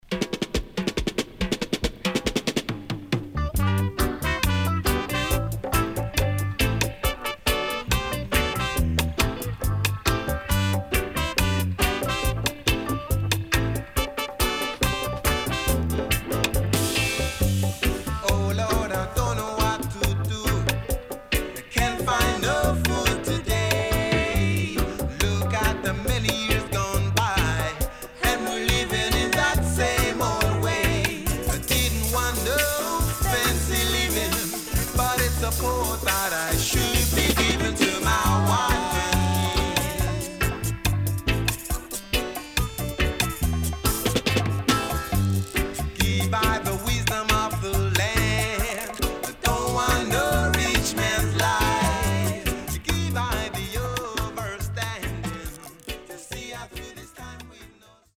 Nice Lovers Vocal
SIDE A:少しチリノイズ入りますが良好です。